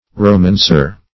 Romancer \Ro*man"cer\, n.